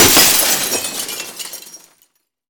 glass_smashable_large_break_01.wav